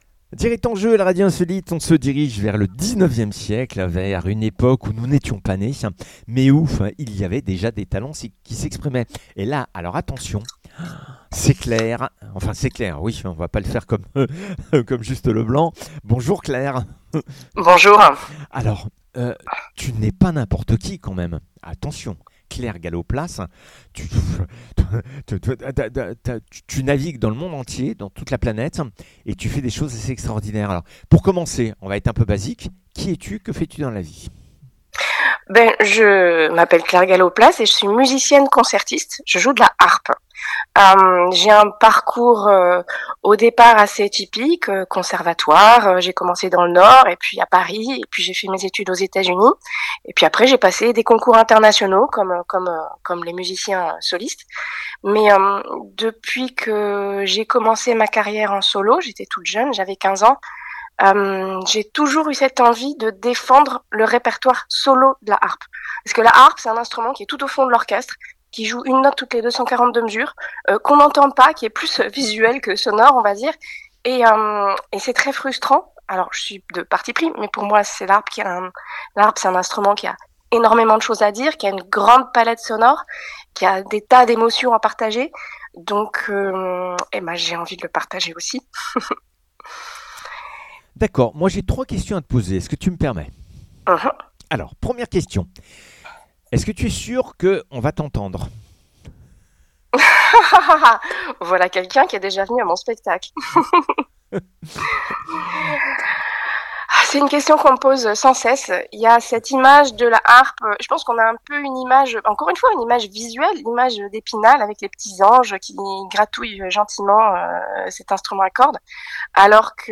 Seule-en-scène musical